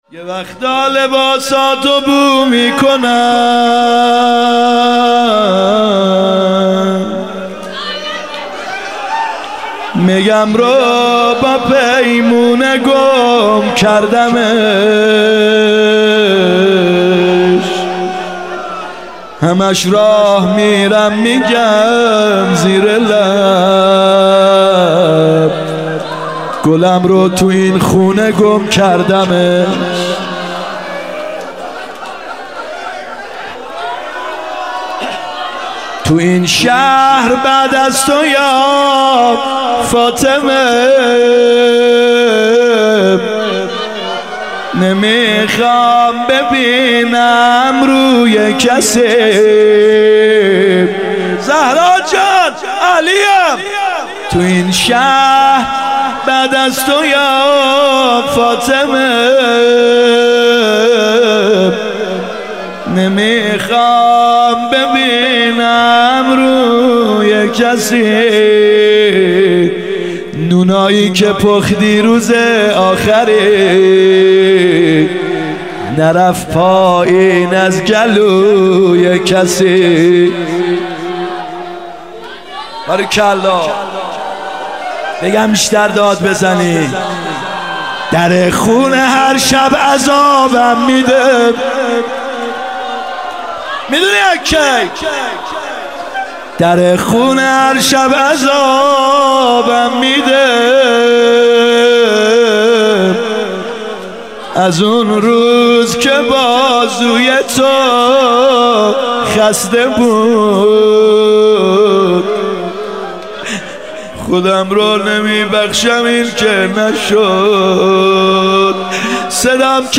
گلچین مراسمات هفتگی